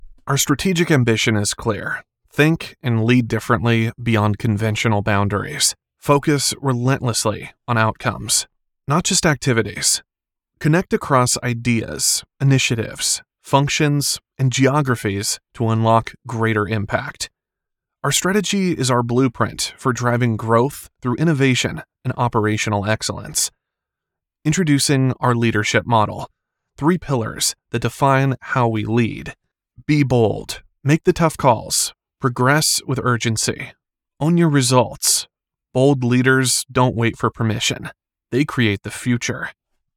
Male
Natural Speaking Voice
Words that describe my voice are young voice over, american voice over, male voice over.